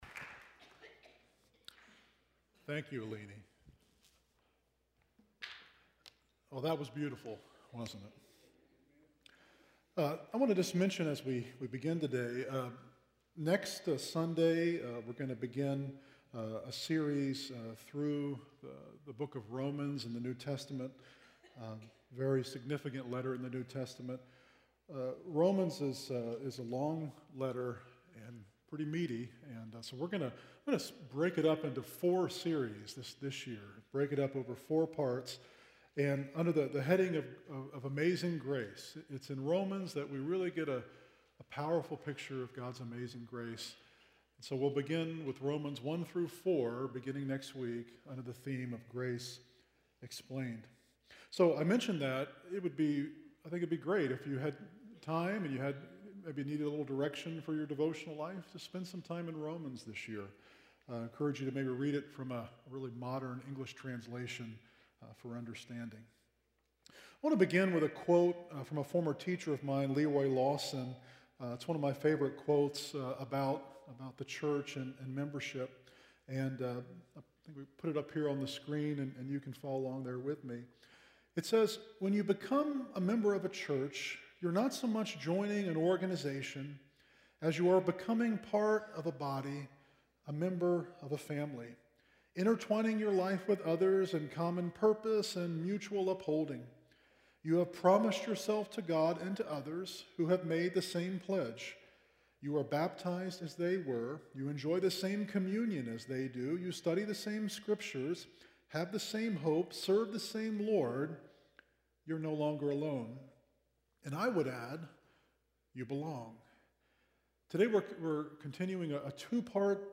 Sermon Series: Belong